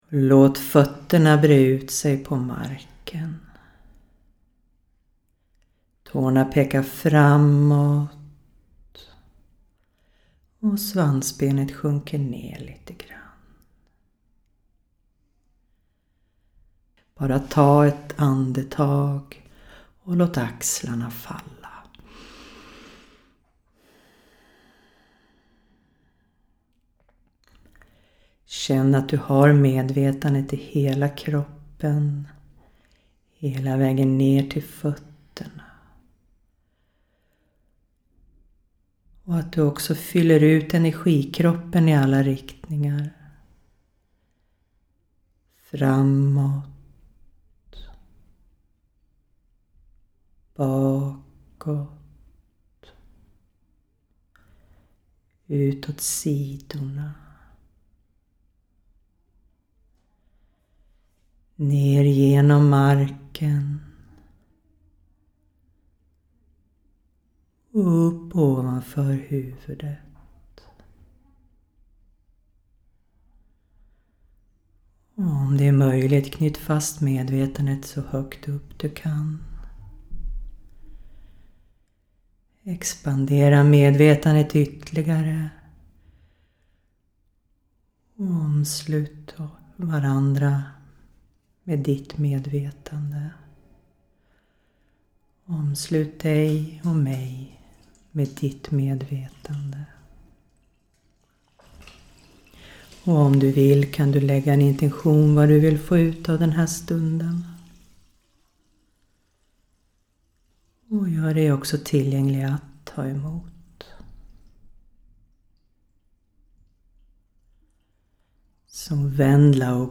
Nivå 2 – Metoden för kropp och medvetande Ljudinspelning av ledord till Metoden (37 min)